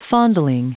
Prononciation du mot fondling en anglais (fichier audio)
Prononciation du mot : fondling